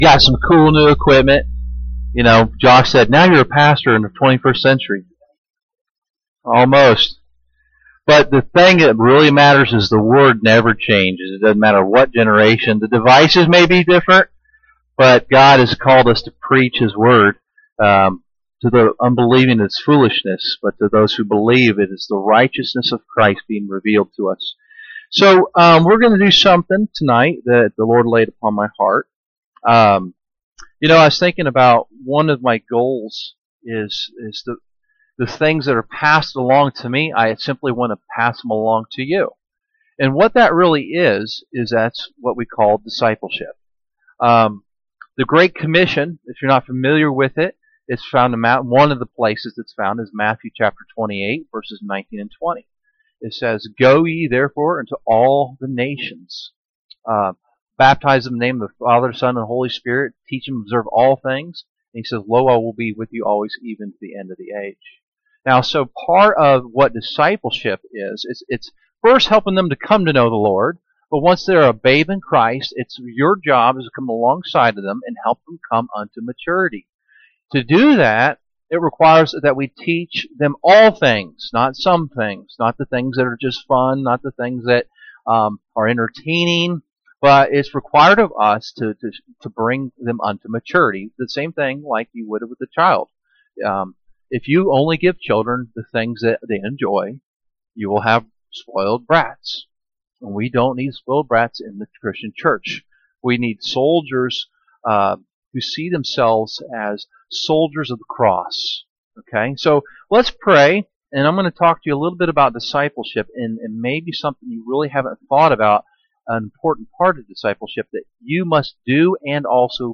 We are working the bugs out of the new sound equipment and apologize for the terrible echo. This will be fixed Sunday, but this was a fantastic message and worth listening to if you can endure the stadium feel.